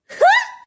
daisy_hoohoo.ogg